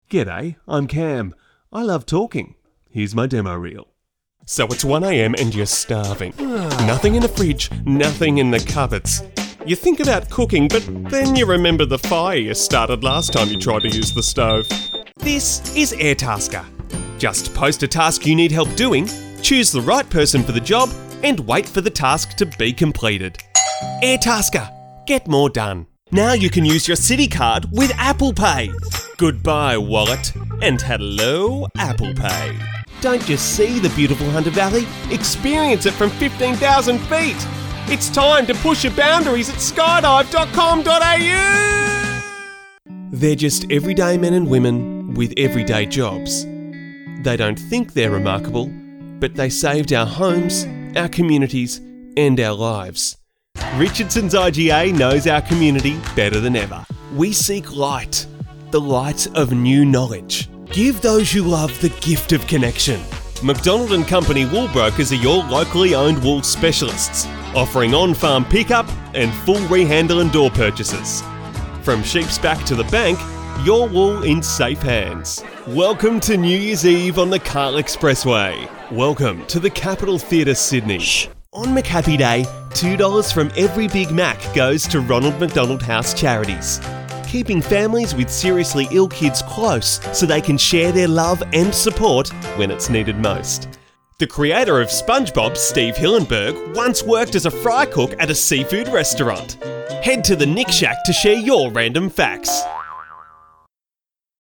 Never any Artificial Voices used, unlike other sites.
Adult (30-50) | Yng Adult (18-29)